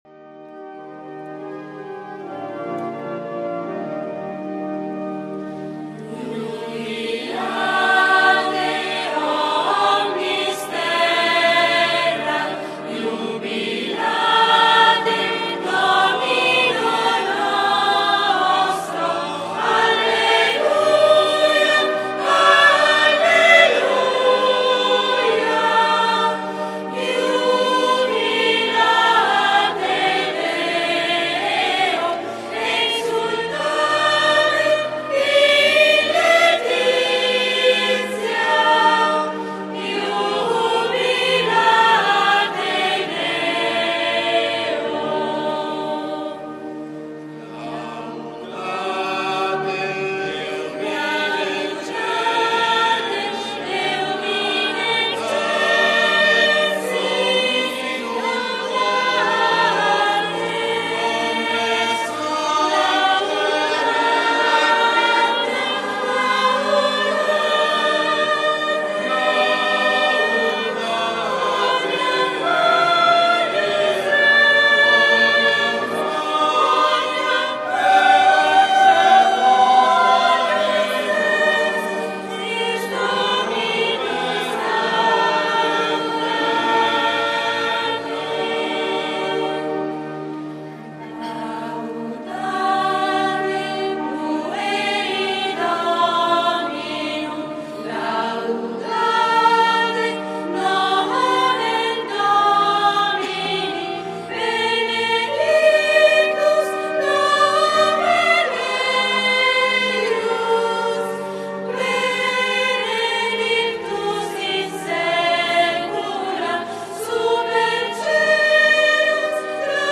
PASQUA DI RESURREZIONE (Pasqua con i ragazzi)